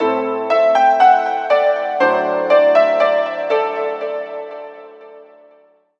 [빰빠바 빰빠 빰빠라빠!